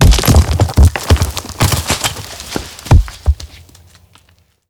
Die Geräusche aus den Regionen Lausanne und Alpes vaudoises, haben Sie bestimmt alle erraten.
C) Steinschlag
steinschlag.wav